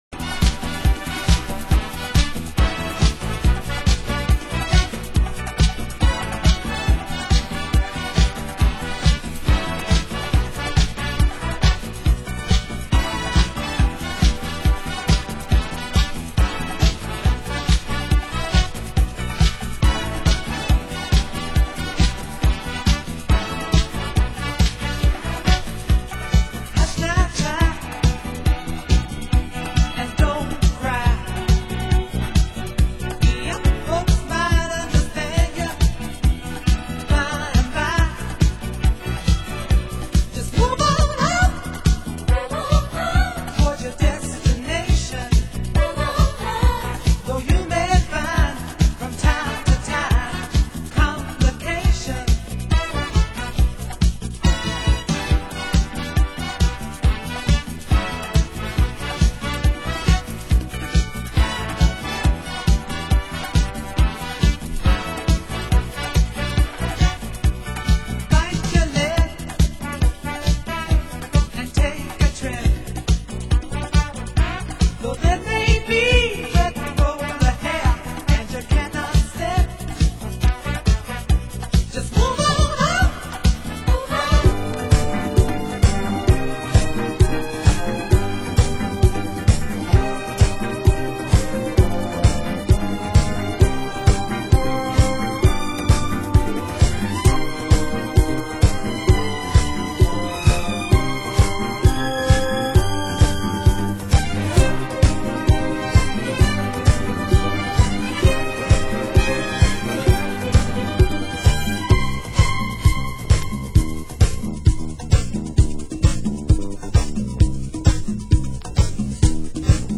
Format: Vinyl 12 Inch
Genre: Disco